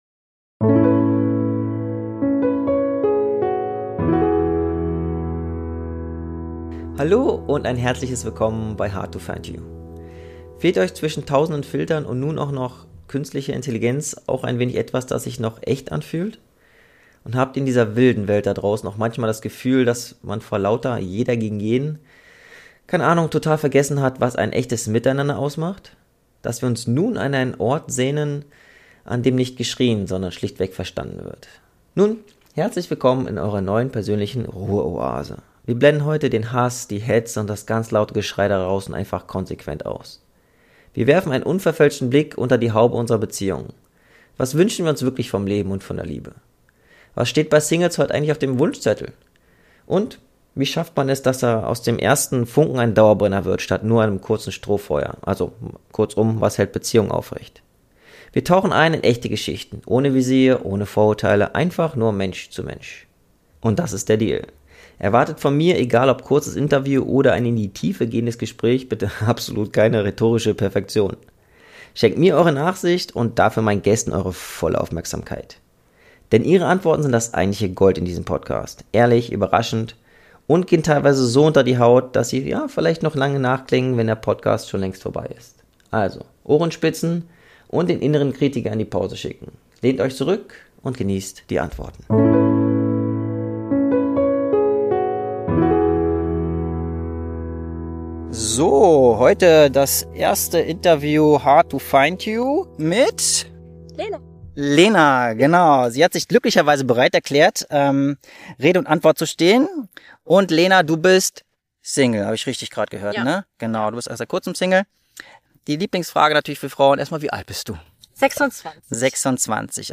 Euch erwarten kurze entspannte Interviews, als auch längere Gespräche die mehr in die Tiefe gehen.